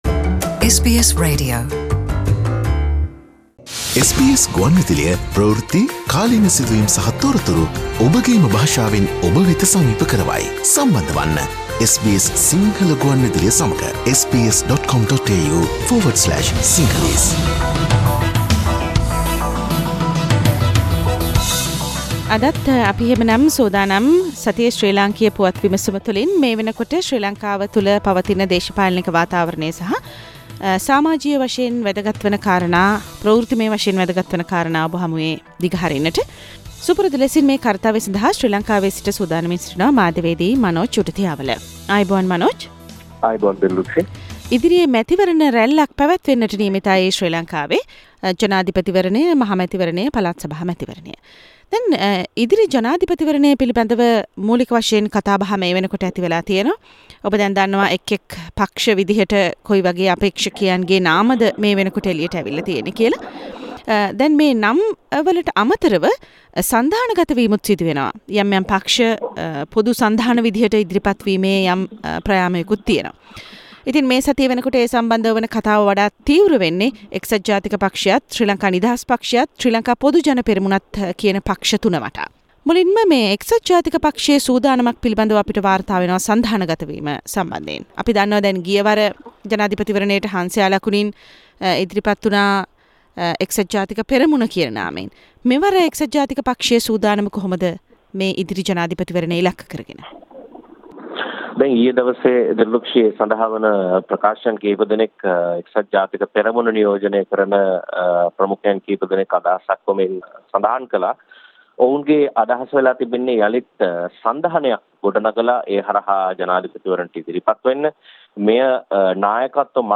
එජාපය අළුත් නමකින් ඉදිරි ජනපතිවරණයට ඒමට සුදානමක් සහ හිස්බුල්ලාටම ෂරියා විශ්ව විද්‍යාලය පැවරීමේ සුදානමක් : සතියේ ශ්‍රී ලංකික පුවත් විමසුම